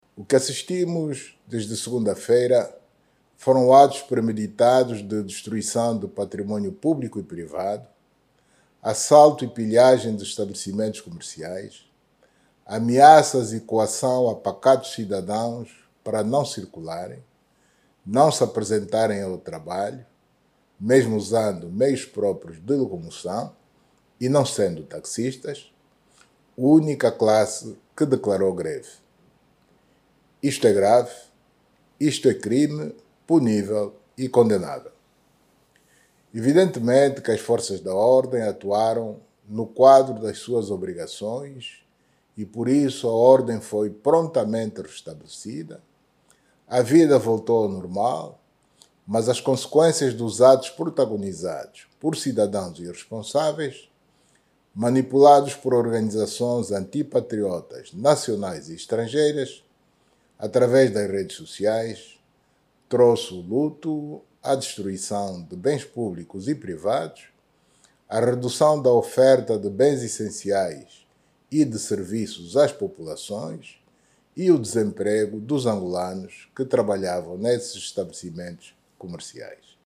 O Chefe de Estado, que endereçou uma mensagem à Nação para condenar os actos de vandalismo e pilhagem, sustentou que estas acções trouxeram luto e destruíram bens públicos e privados, pondo em causa muitos postos de trabalho.